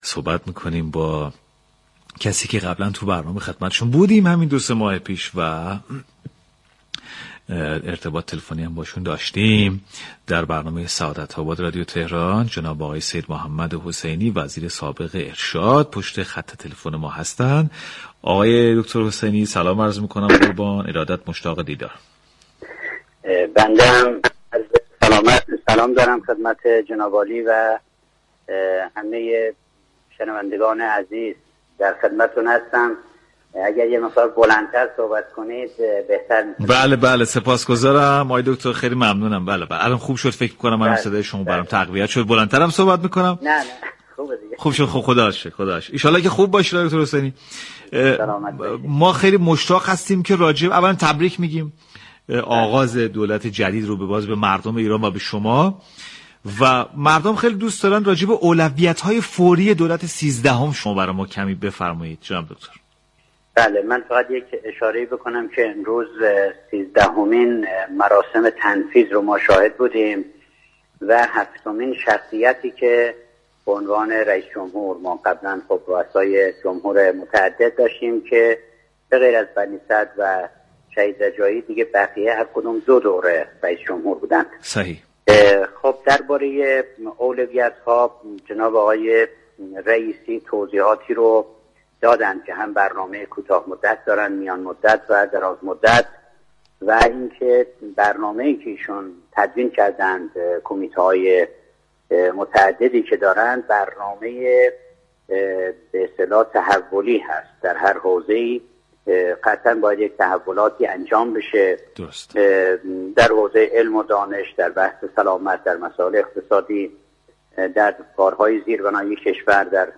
به گزارش پایگاه اطلاع رسانی رادیو تهران، سیدمحمد حسینی وزیر پیشین فرهنگ و ارشاد اسلامی در گفتگو با برنامه سعادت آباد رادیو تهران درباره اولویت‌های دولت سیزدهم گفت: آقای رئیسی برنامه‌های كوتاه مدت، میان مدت و دراز مدت دارند و عمده برنامه‌های ایشان در حوزه‌های مختلف از جمله علم و دانش، سلامت، اقتصاد و سیاست خارجی و...، تحول محور خواهد بود.